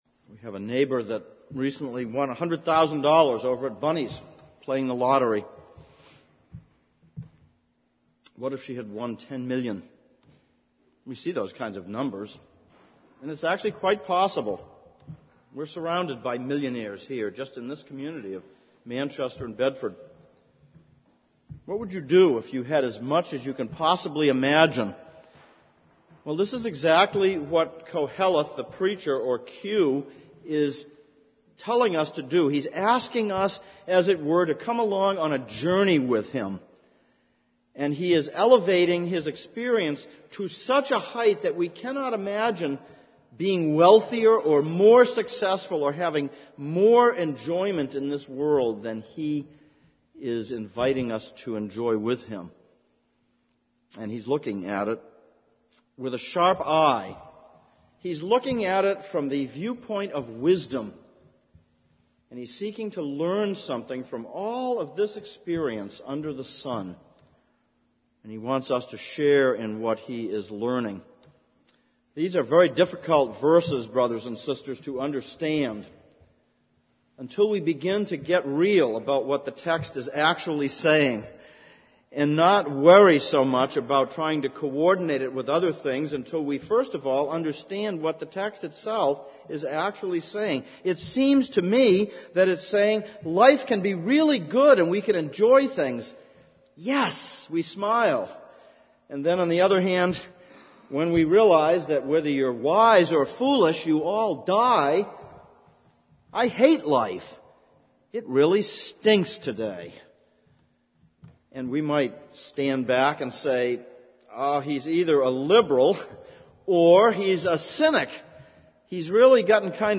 Exposition of Ecclesiastes Passage: Ecclesiastes 2:1-17 Service Type: Sunday Evening « 02.